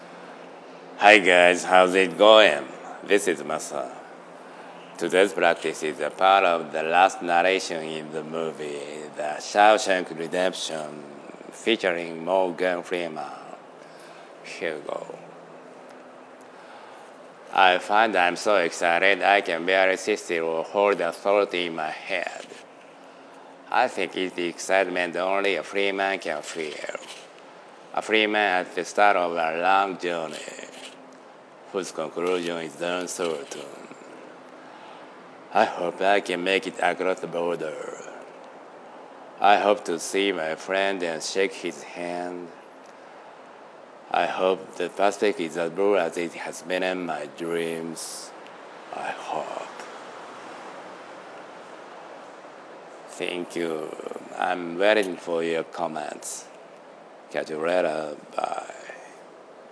A Part of the Last Narration in the Movie `The Shawshank Redemption’